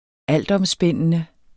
Udtale [ -ˌʌmˌsbεnˀənə ]